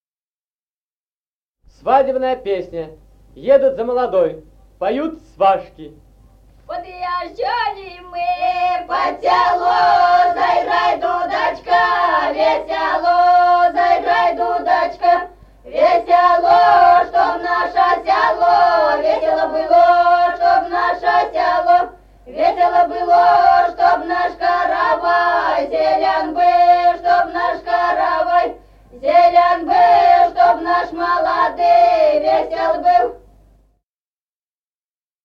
Музыкальный фольклор села Мишковка «Подъезжали мы под село», свадебная.